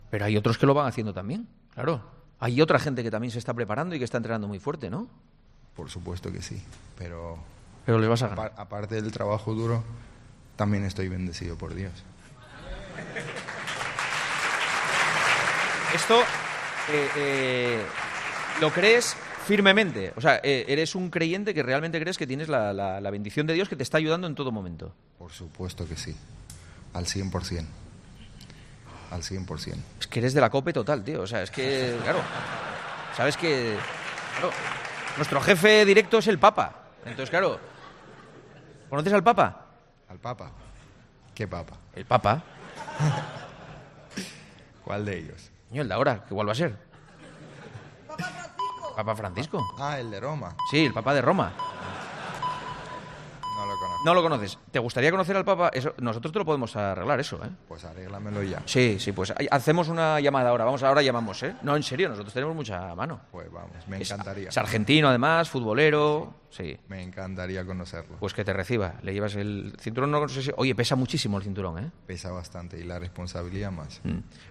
La creencia en Dios del campeón del peso pluma de la UFC es una de las claves de su éxito y ahora quiere conocer al Papa Francisco, como confesó en El Partidazo de COPE especial de este miércoles en Alicante.